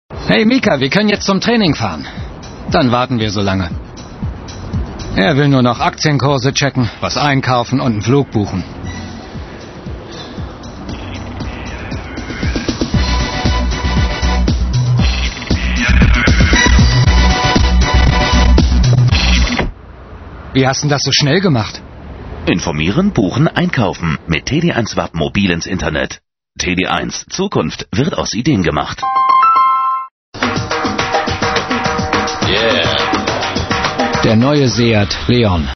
deutscher Synchronsprecher.
Kein Dialekt
Sprechprobe: eLearning (Muttersprache):